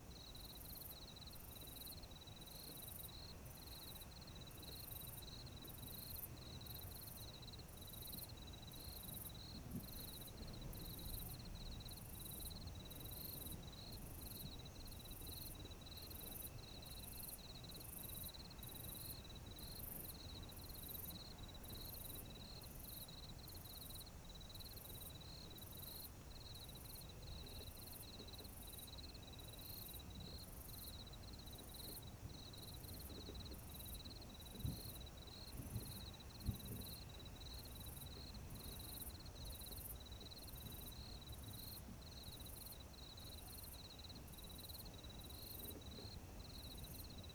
houses_night.ogg